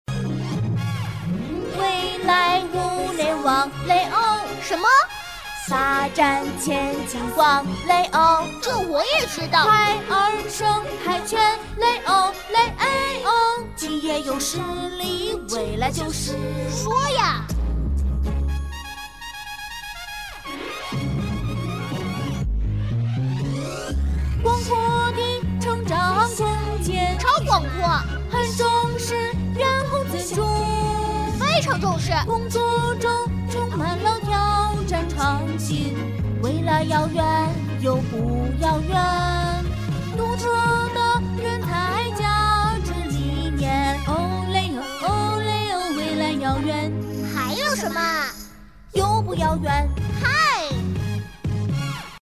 女C3-百变女王 可爱